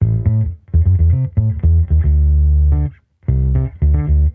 Index of /musicradar/sampled-funk-soul-samples/110bpm/Bass
SSF_PBassProc2_110A.wav